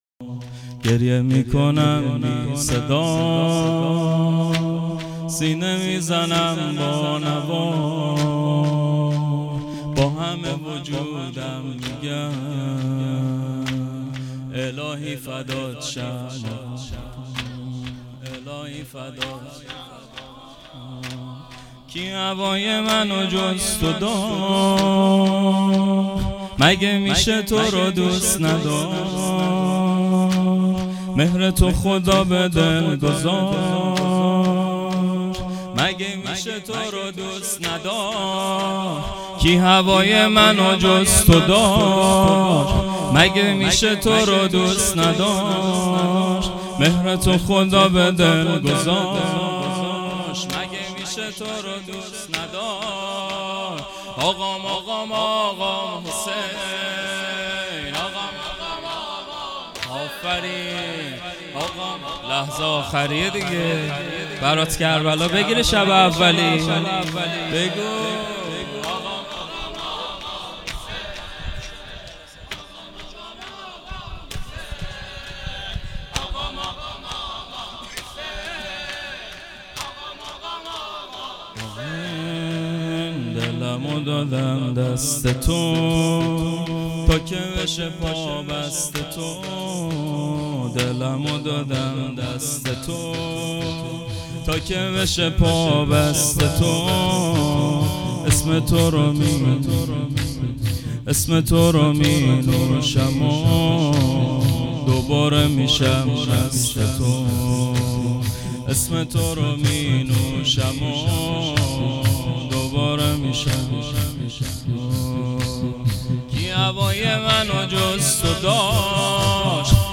شور گریه میکنم بی صدا